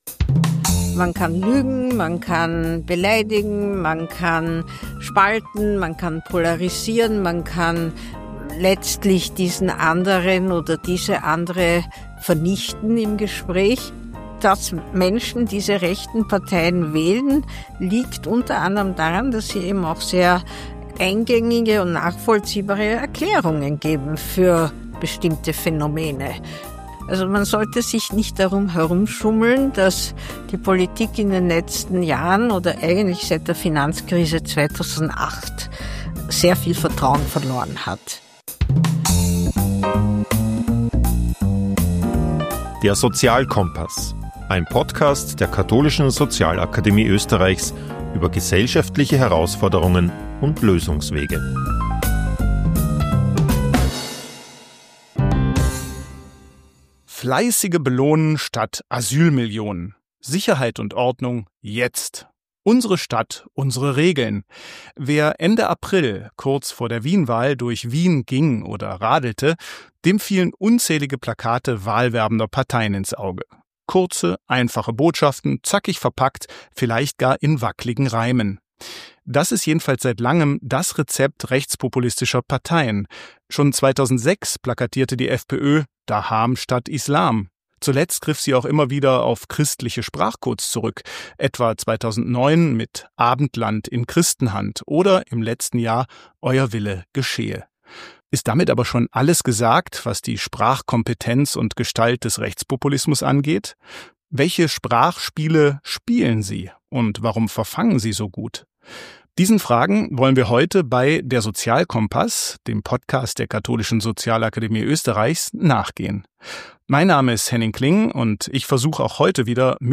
Wir sprechen mit der Sprachwissenschaftlerin Ruth Wodak über die Sprache des Rechtspopulismus: Wie – und warum – wird politische Sprache, die auf Ausgrenzung anderer basiert, gesellschaftlich normalisiert?